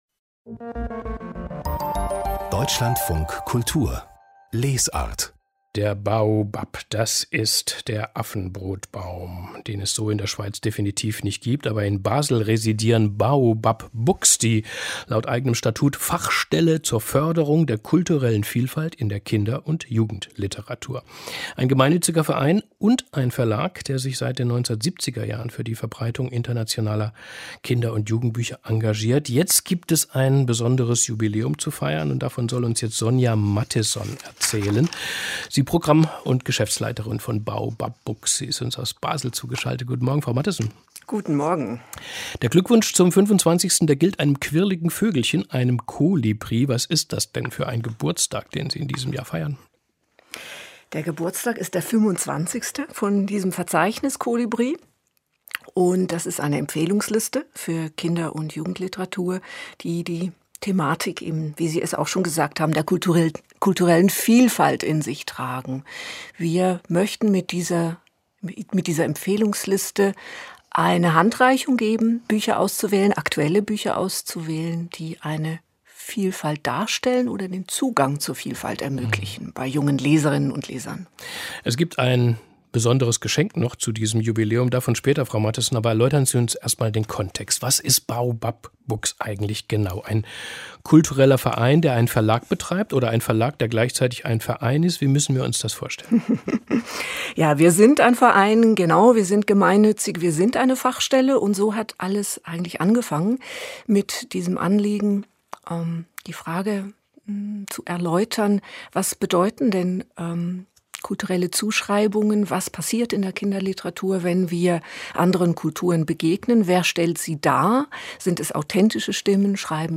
Zum Radiobeitrag im DLF Kultur vom 5.2.2019